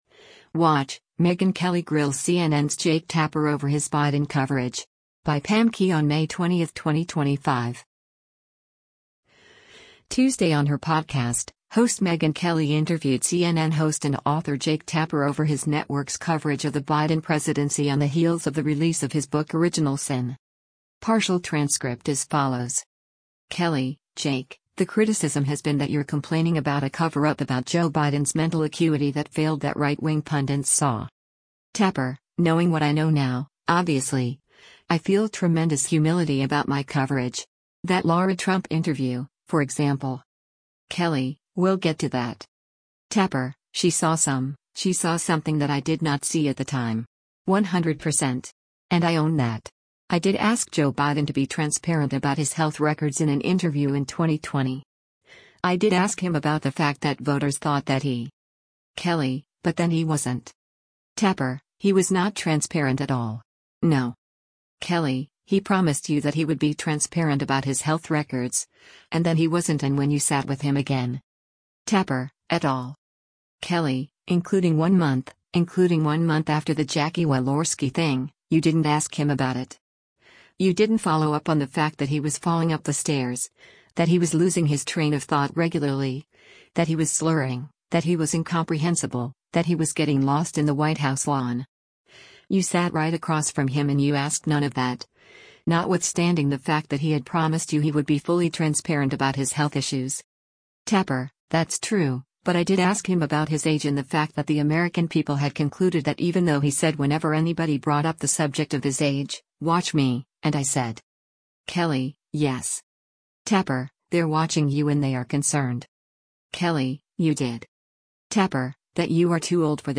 Tuesday on her podcast, host Megyn Kelly interviewed CNN host and author Jake Tapper over his network’s coverage of the Biden presidency on the heels of the release of his book “Original Sin.”